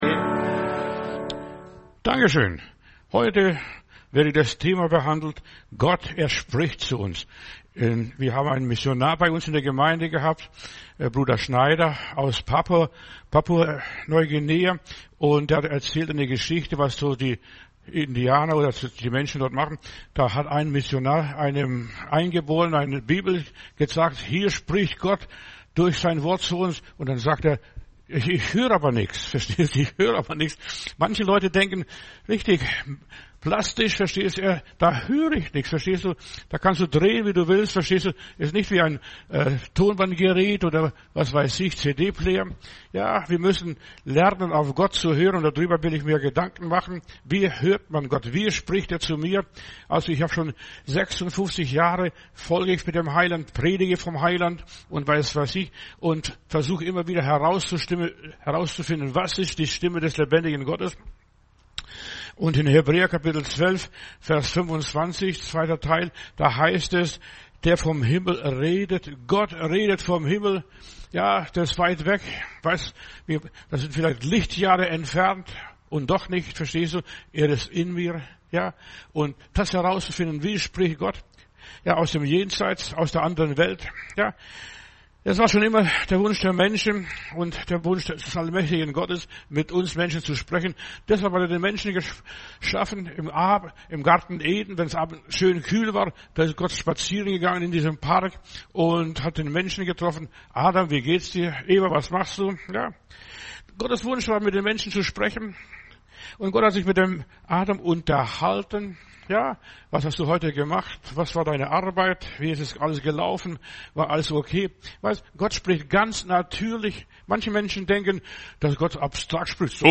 Predigt herunterladen: Audio 2025-01-15 ER spricht Video ER spricht